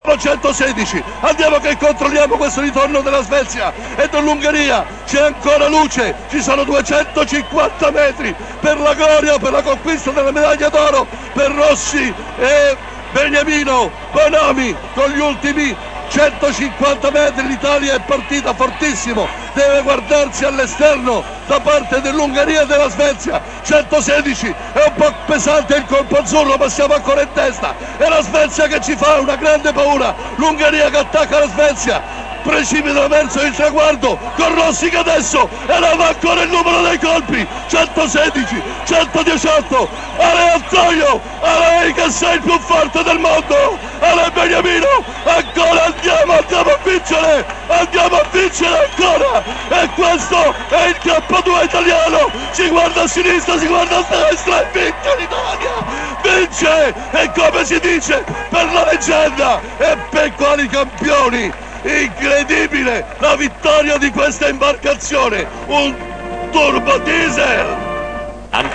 Telecronaca Galeazzi - Rossi e Beniamino Bonomi ORO K2
Galeazzi - Telecronaca canottaggio.mp3